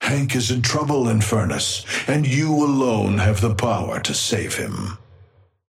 Amber Hand voice line - Hank is in trouble, Infernus, and you alone have the power to save him.
Patron_male_ally_inferno_start_02.mp3